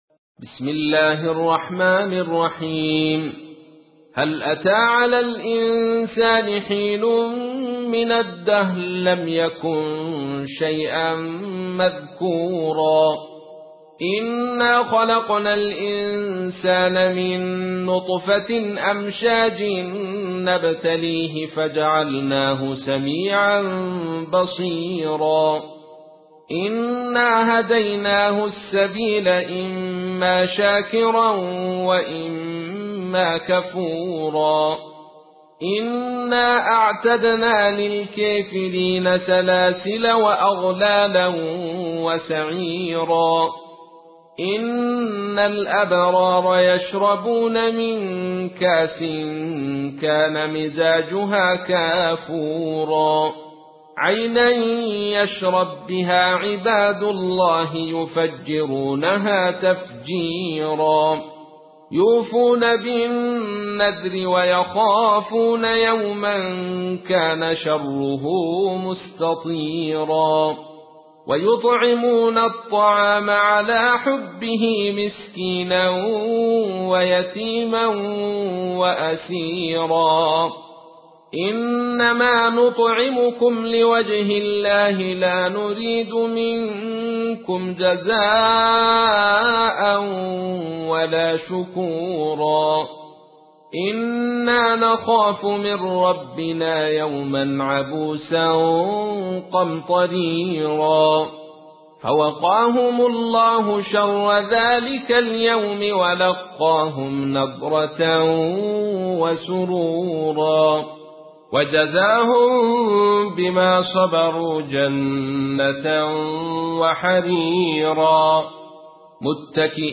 تحميل : 76. سورة الإنسان / القارئ عبد الرشيد صوفي / القرآن الكريم / موقع يا حسين